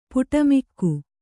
♪ puṭamikku